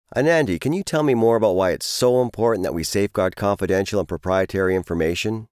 North American deep voice, warm serious
Sprechprobe: eLearning (Muttersprache):
Movie trailer voice to warm and mysterious